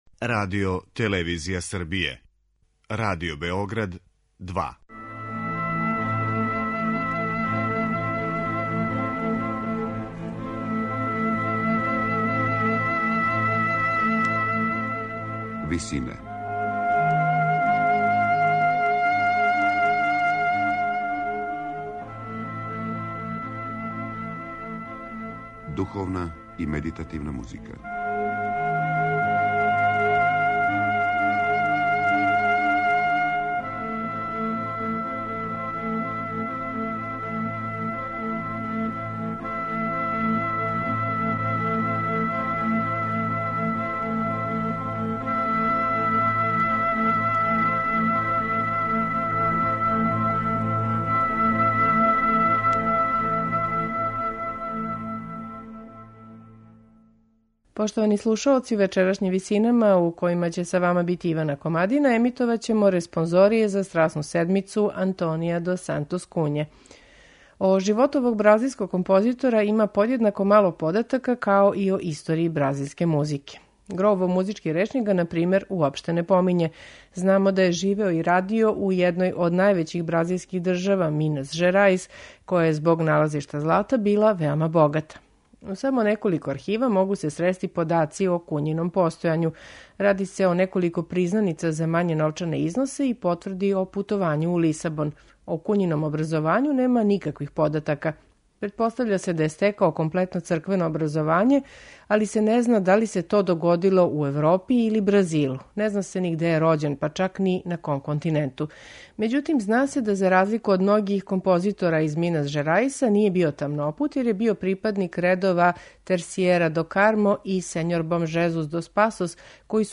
медитативне и духовне композиције